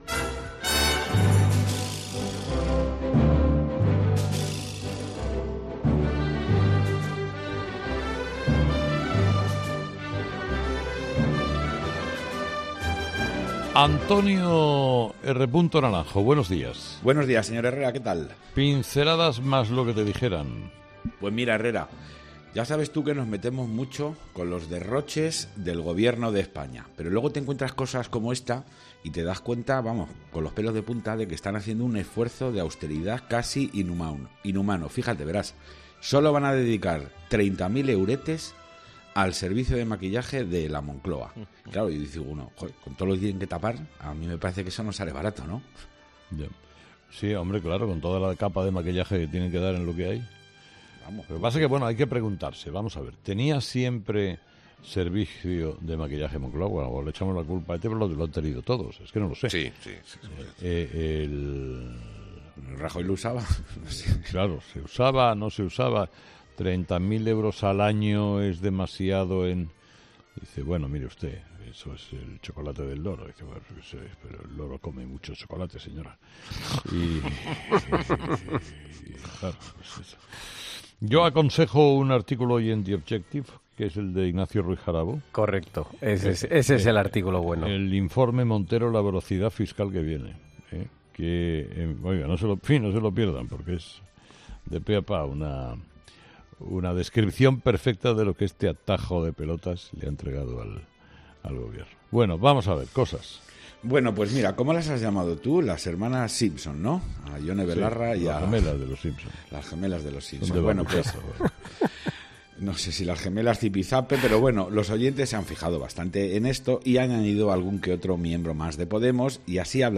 AUDIO: Los oyentes, de nuevo, protagonistas en 'Herrera en COPE' con su particular tertulia.